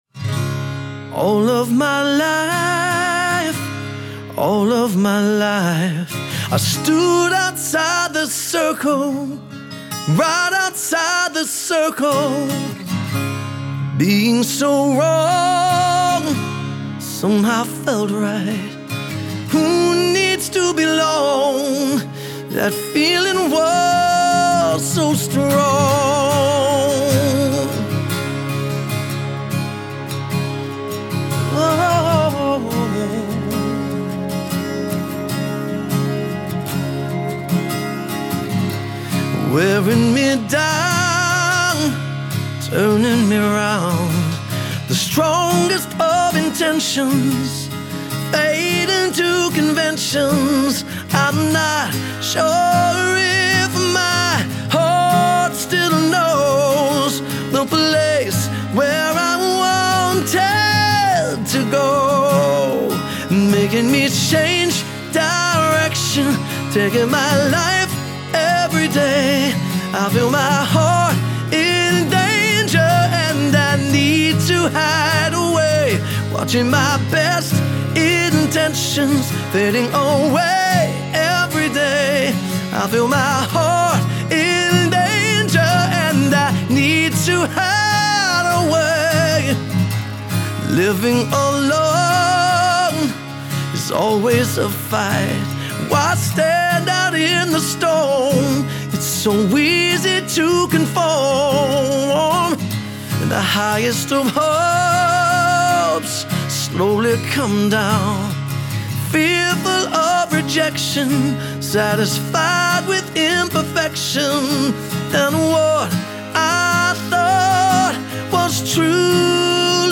lead singer/guitarist
Acoustic